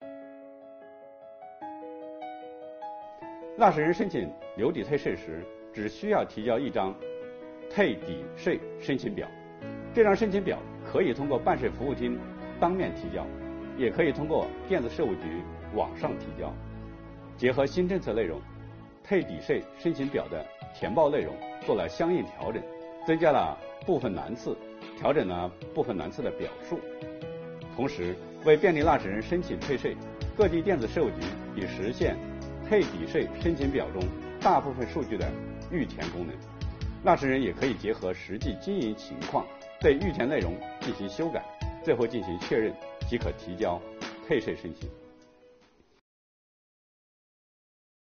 本期课程由国家税务总局货物和劳务税司副司长刘运毛担任主讲人，对2022年增值税留抵退税新政进行详细讲解，方便广大纳税人更好地理解和享受政策。